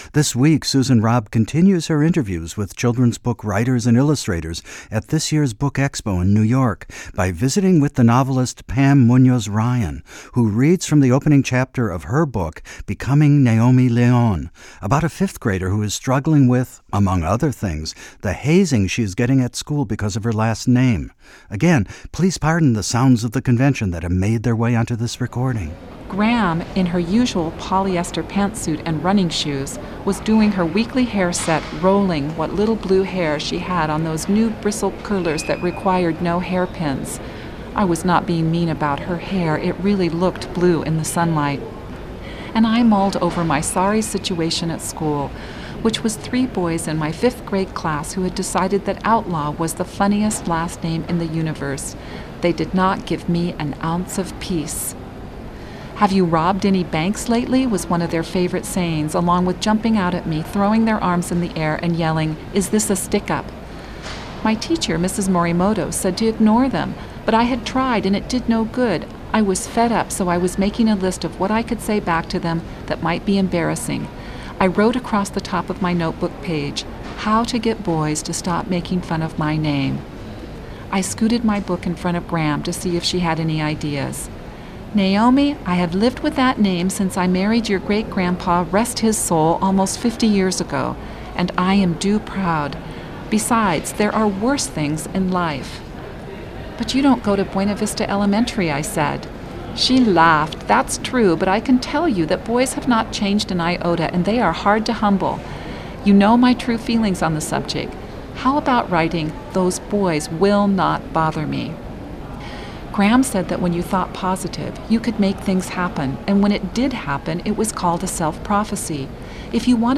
Again, please pardon the sounds of the convention that have made their way onto this recording.